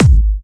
VEC3 Bassdrums Trance 56.wav